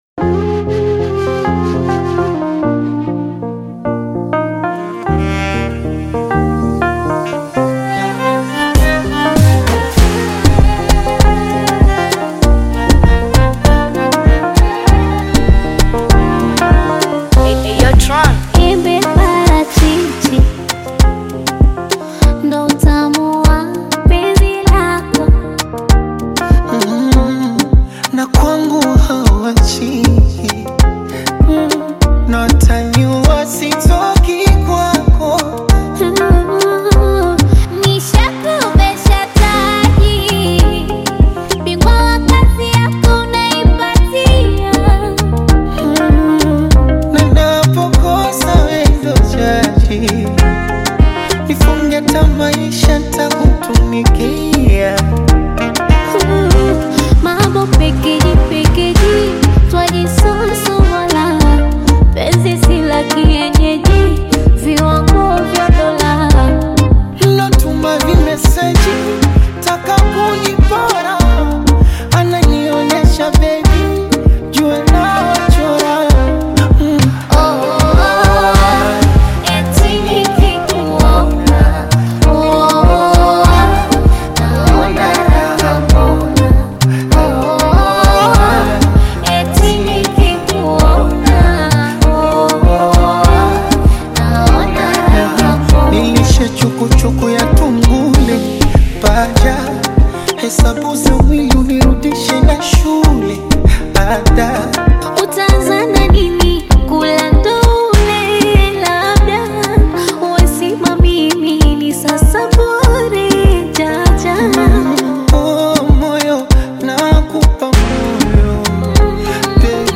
beautiful melodies from the female singer